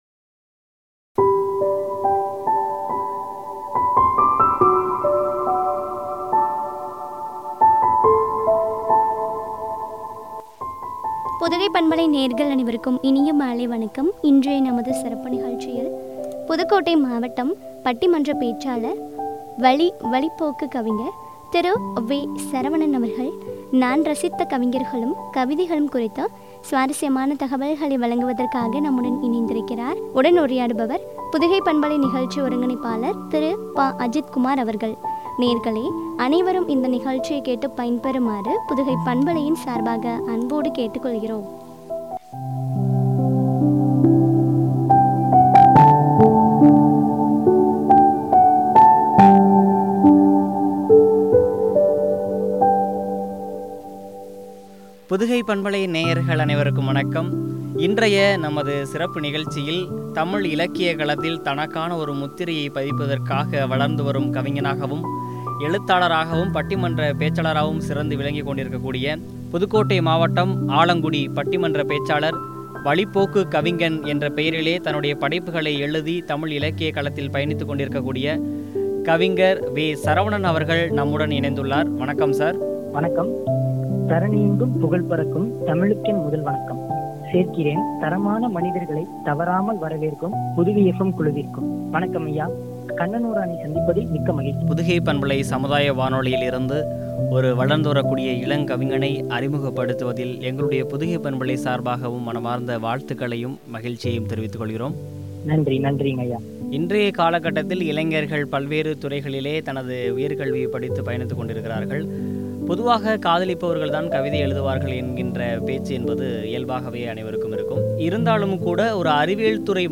கவிதைகளும்” குறித்து வழங்க உரையாடல்.